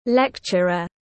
Giảng viên tiếng anh gọi là lecturer, phiên âm tiếng anh đọc là /ˈlektʃərər/.
Lecturer /ˈlektʃərər/
Lecturer.mp3